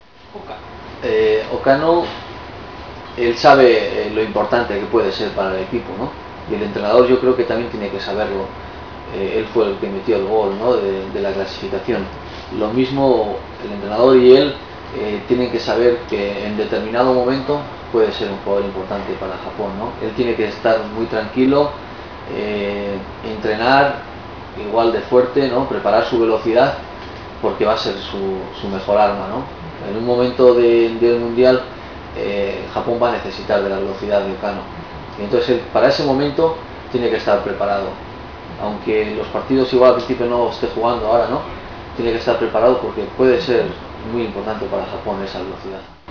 ワールドカップ出場経験者であるベギリスタイン選手にワールドカップについて、そして浦和レッズから代表として出場する３人の選手についてのコメントを語ってもらいました。